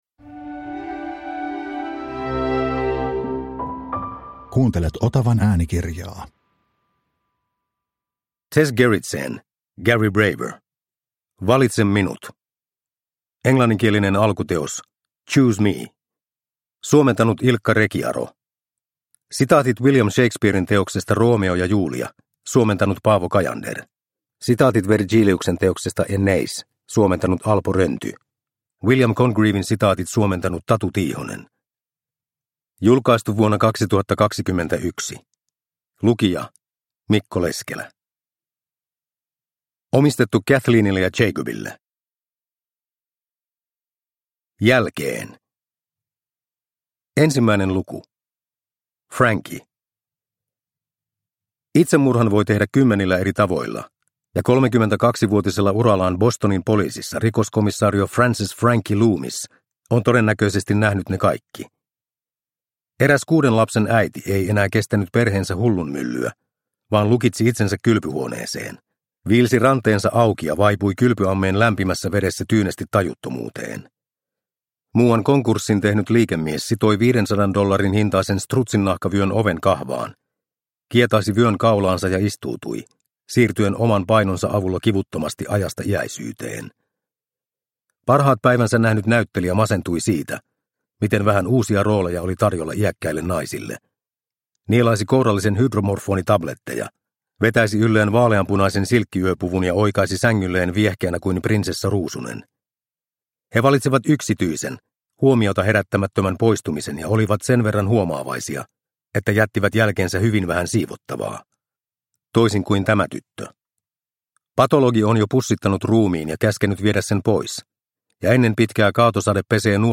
Valitse minut – Ljudbok – Laddas ner